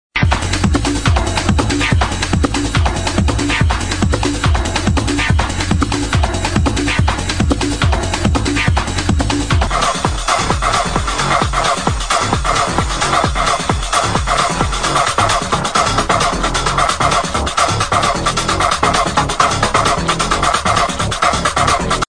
Dunno popular techno songs- corection